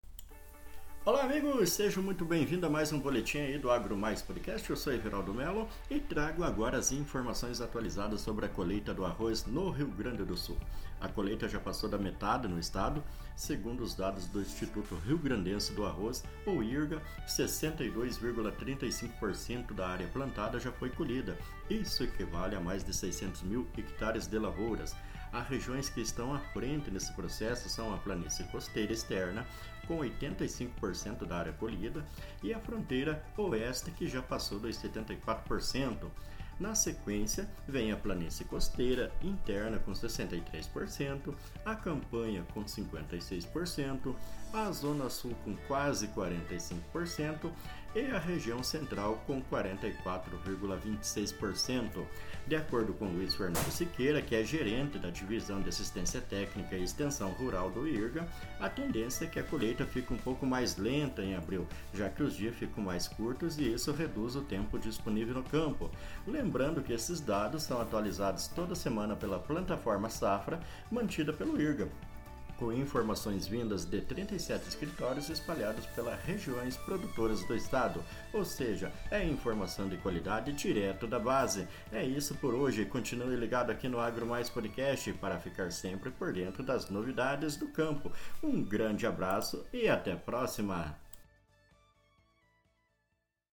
Boletim-AgroPodcast1.mp3